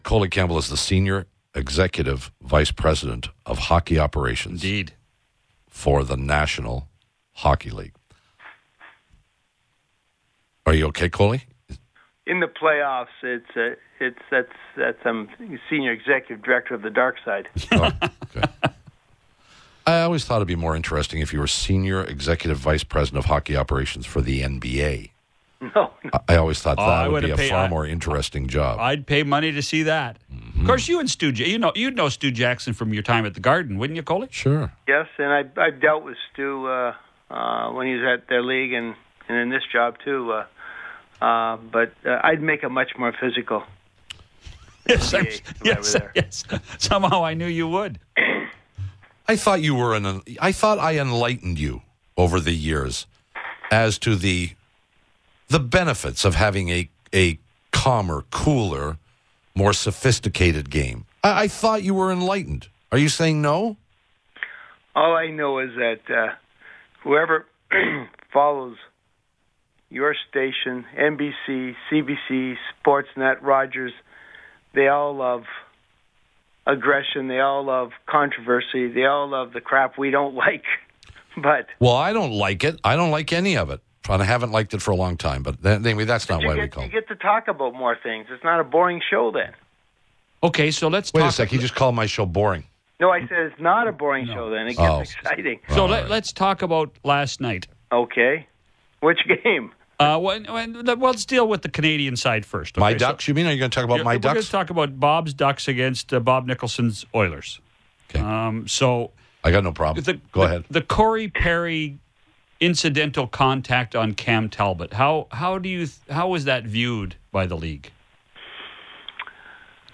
Colin Campbell explains controversial non-call in Oilers-Ducks Game 4
Colin Campbell, the NHL’s senior executive VP of hockey operations, joined Prime Time Sports on Thursday to further clarify any remaining confusion about Perry’s contact with Talbot.